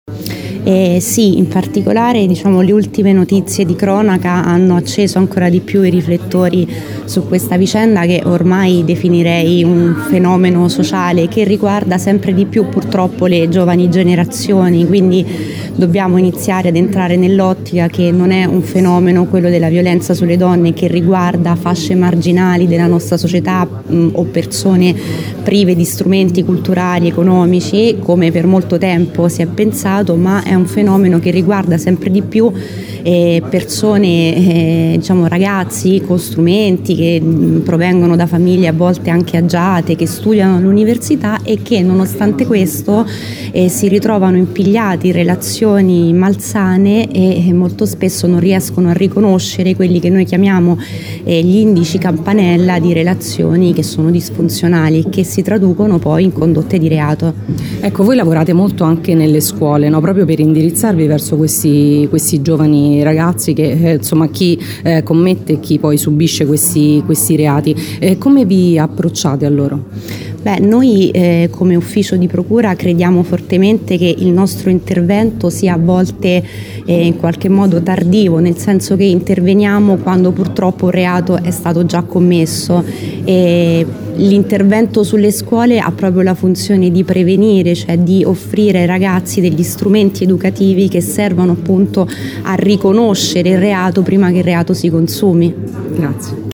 L’evento, che precede la Giornata Internazionale per l’eliminazione della violenza contro le donne, si è tenuto nella sala conferenze del Polo Pontino della Sapienza con la partecipazione di molti attori istituzionali.
Le interviste contenute in questo articolo
SOSTITUTA PROCURATRICE MARTINA TAGLIONE DELLA PROCURA DELLA REPUBBLICA DI LATINA